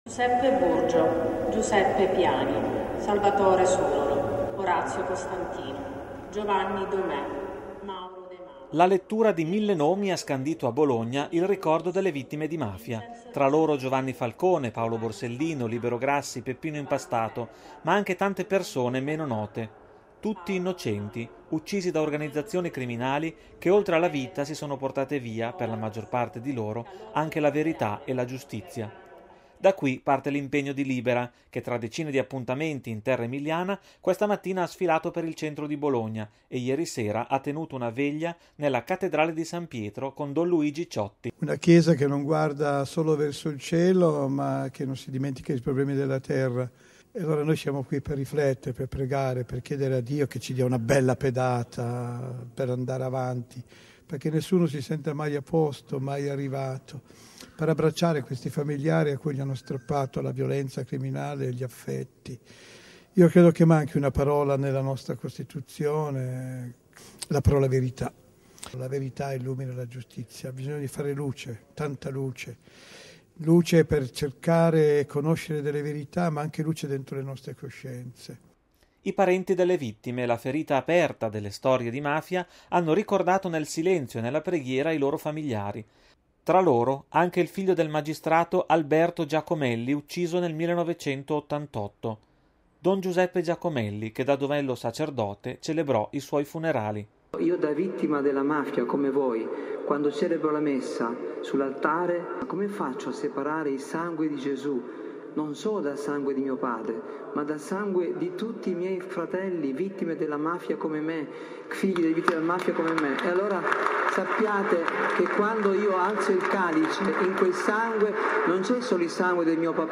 Il servizio da Bologna